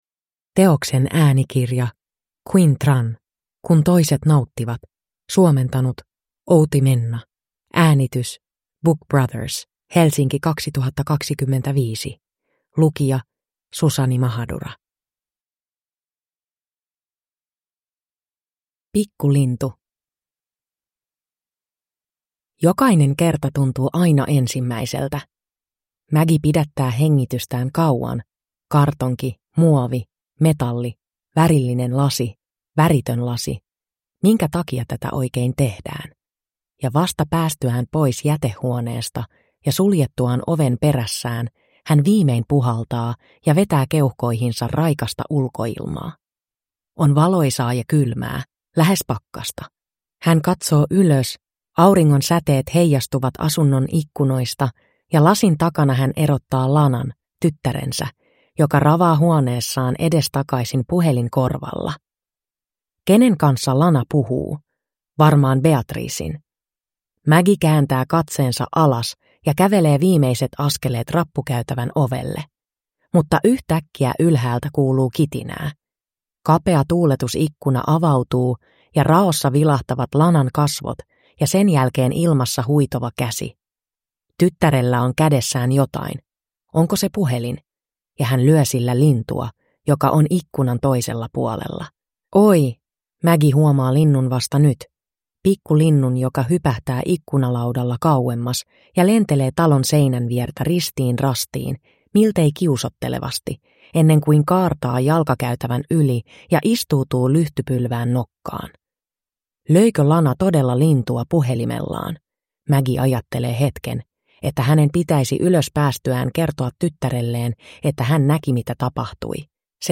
Kun toiset nauttivat (ljudbok) av Quynh Tran